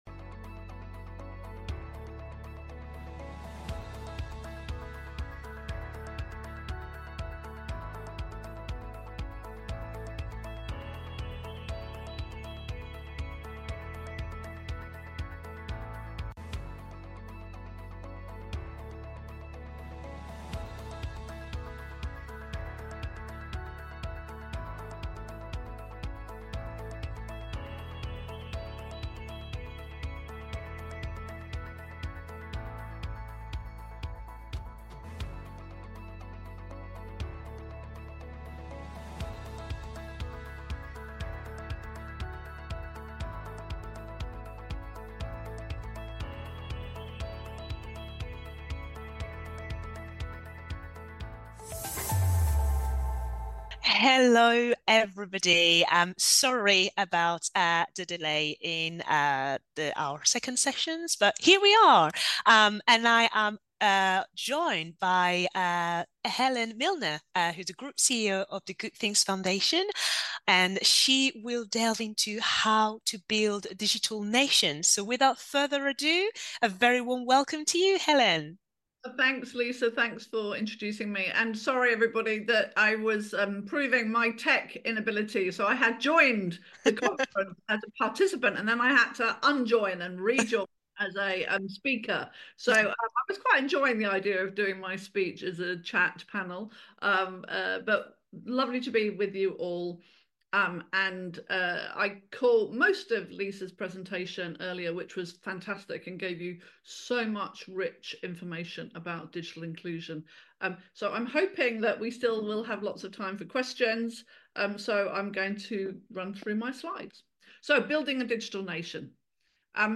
In this podcast, recorded at Charity Digital's Digital Inclusion Summit ...